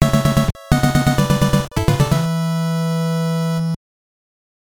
Звук завершенного этапа